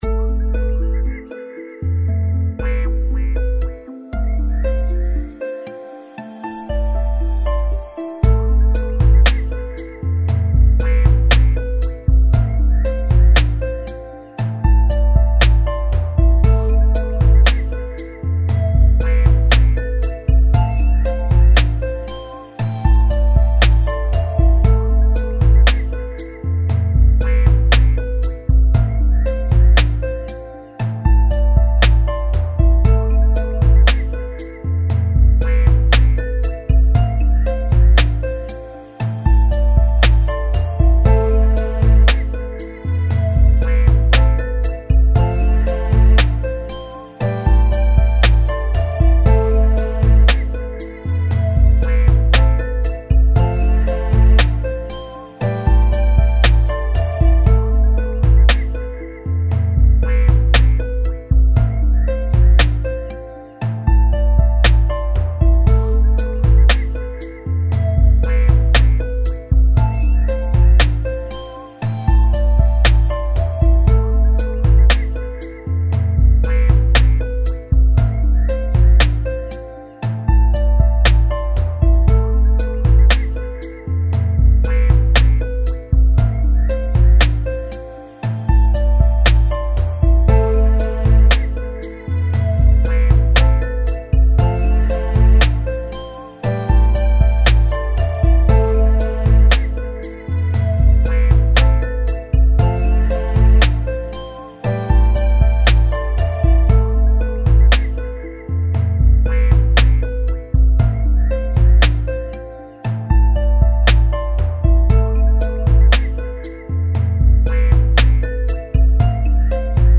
Echoing sounds, nature sound effects, sets the mood right.
117 BPM.